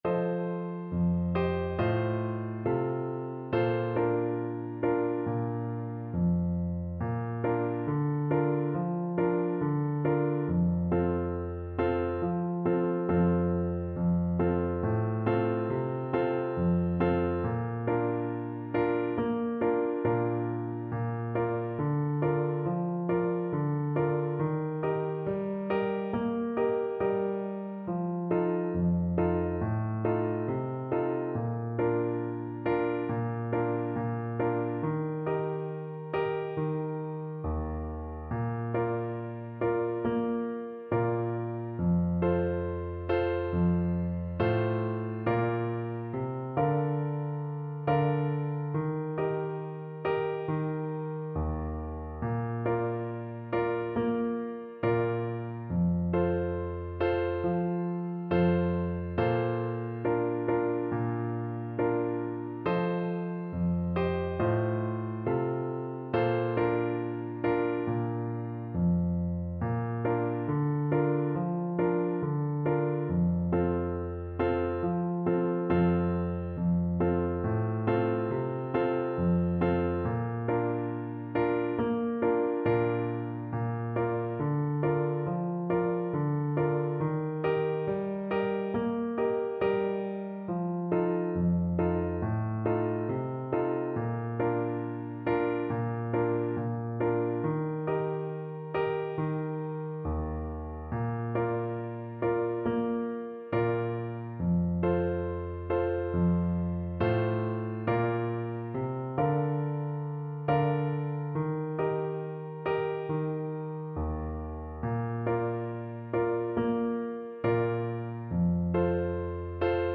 Clarinet
Bb major (Sounding Pitch) C major (Clarinet in Bb) (View more Bb major Music for Clarinet )
Gently =c.100
4/4 (View more 4/4 Music)
Traditional (View more Traditional Clarinet Music)